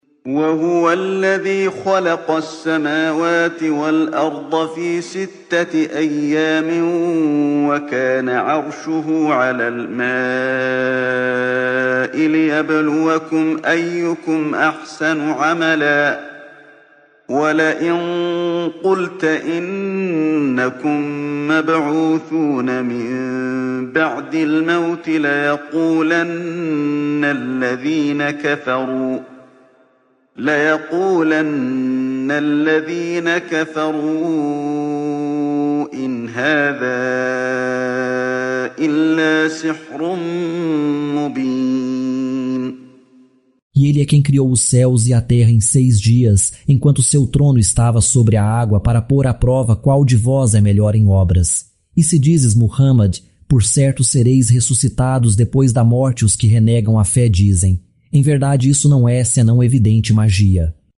قراءة صوتية باللغة البرتغالية لمعاني سورة هود مقسمة بالآيات، مصحوبة بتلاوة القارئ علي بن عبد الرحمن الحذيفي.